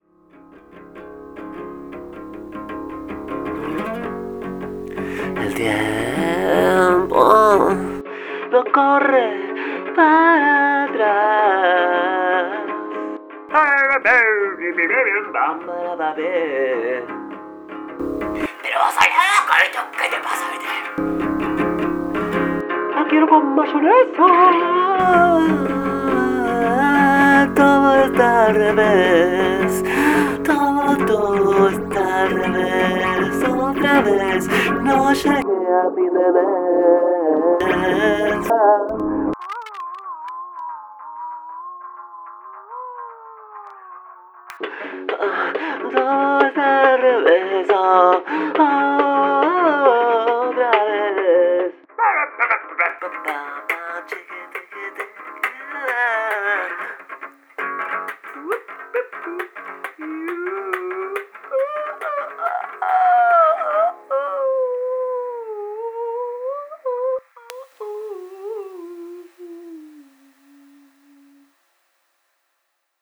Usé el Audacity para grabar y editar.